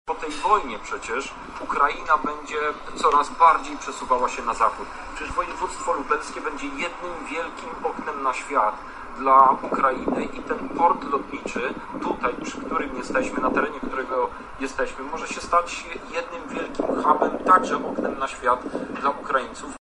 • mówi Krzysztof Hetman z Polskiego Stronnictwa Ludowego.